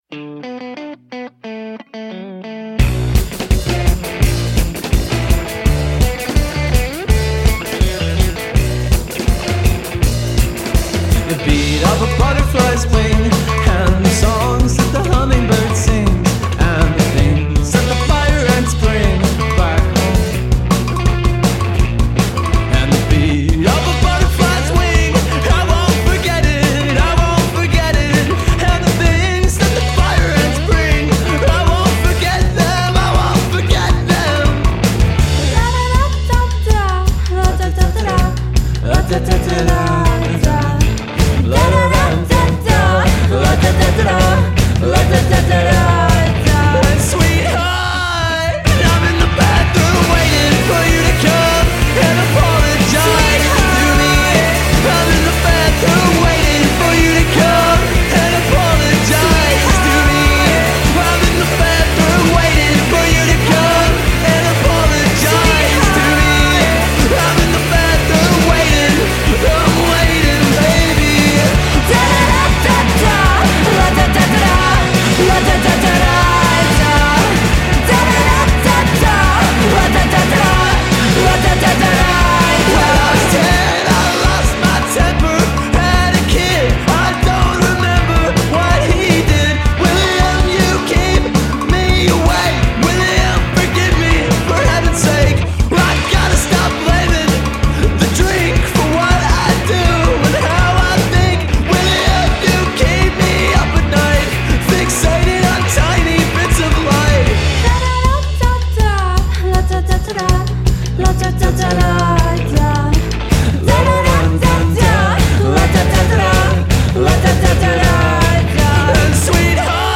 UK garage rockers